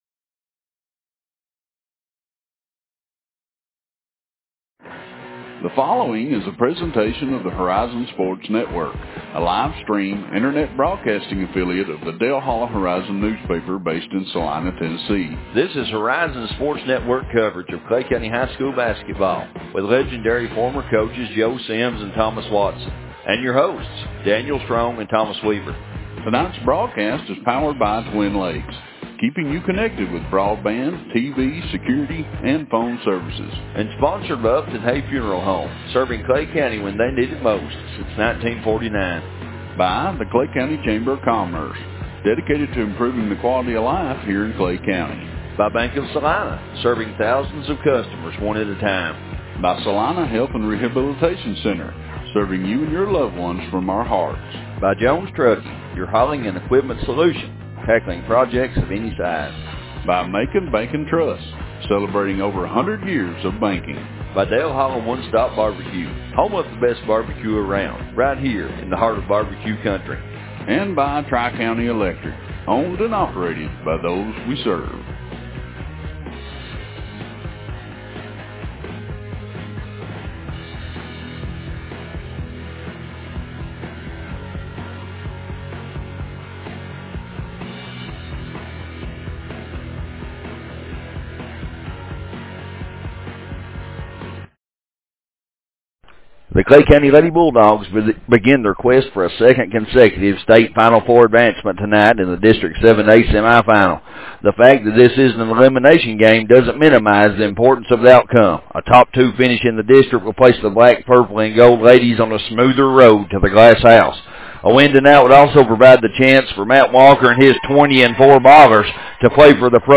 2022 Clay County High School basketball season broadcasts - Dale Hollow Horizon